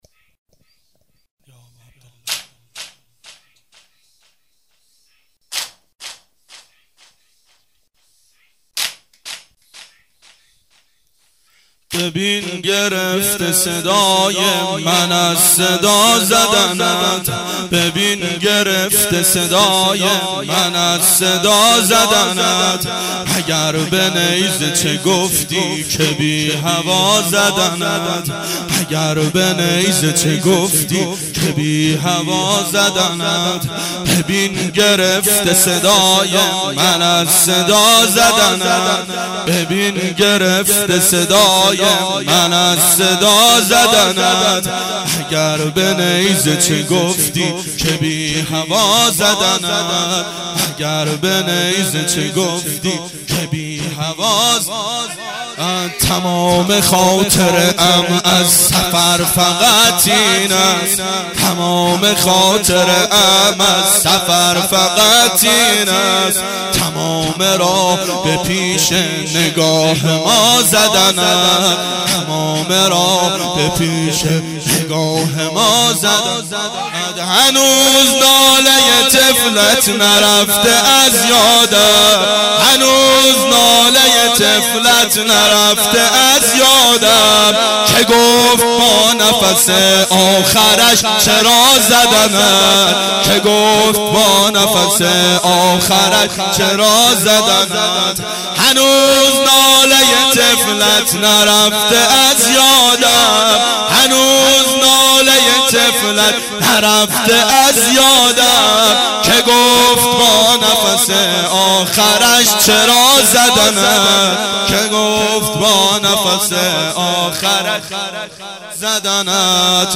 • ظهر اربعین سال 1388 محفل شیفتگان حضرت رقیه سلام الله علیها